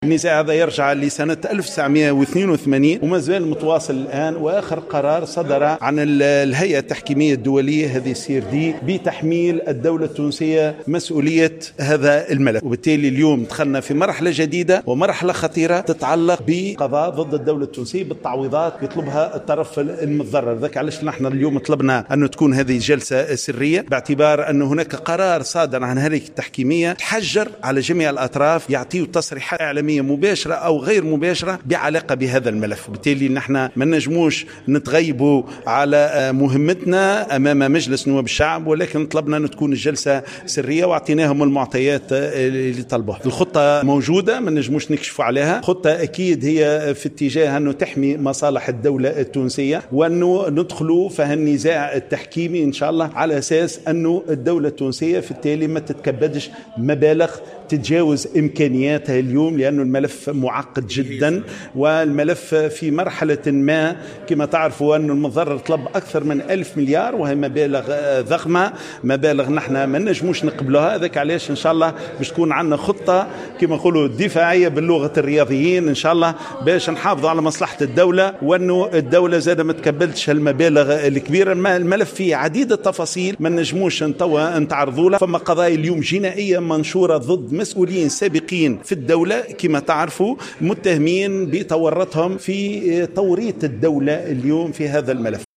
قال وزير أملاك الدولة والشؤون العقارية غازي الشواشي في تصريح للجوهرة "اف ام" اثر جلسة سرية جمعته مع أعضاء لجنة الاصلاح الإداري والحوكمة ومقاومة الفساد حول ملف البنك الفرنسي التونسي أن المتضرر طالب بألف مليار كتعويض من الدولة التونسية.